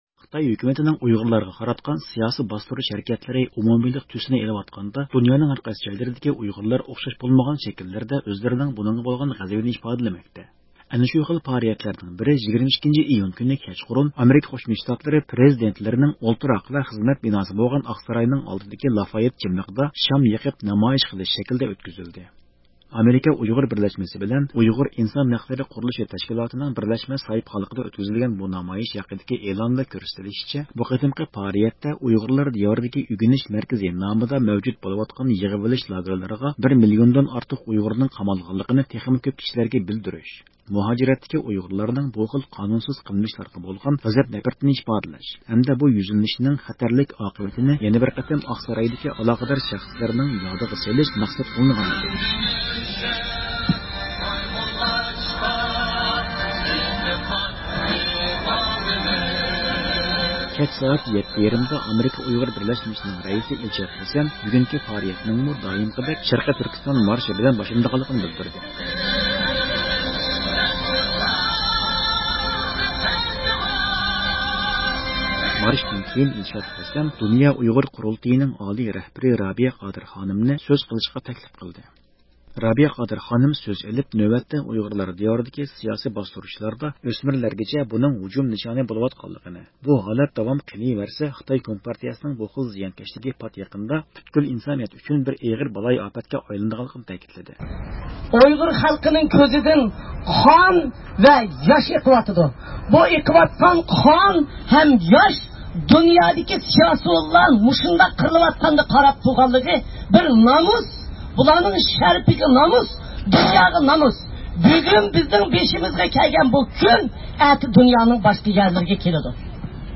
شۇنىڭدىن كېيىن بۈگۈنكى پائالىيەتكە ئىشتىراك قىلغانلار بىرلىكتە شوئار توۋلىدى.